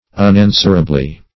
Meaning of unanswerably. unanswerably synonyms, pronunciation, spelling and more from Free Dictionary.